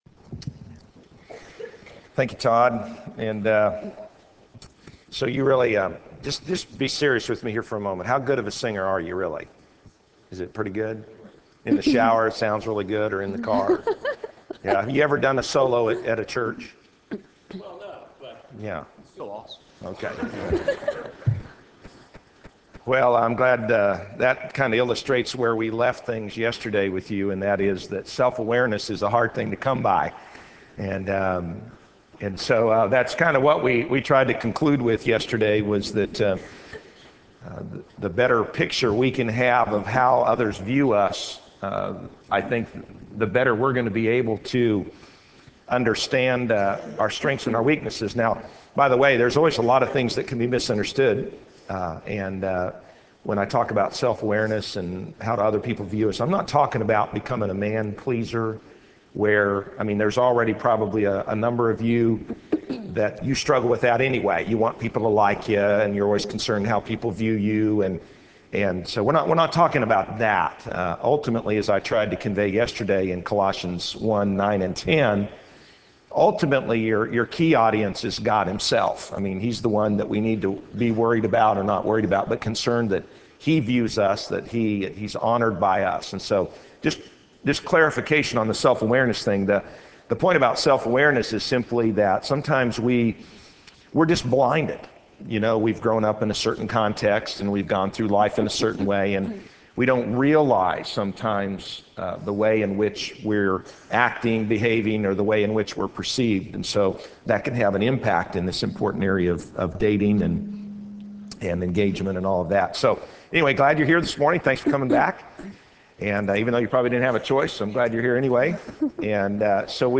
Crabtree Family Life Series Chapel
Address: Developing Healthy Relationships: Getting Together Right Recording Date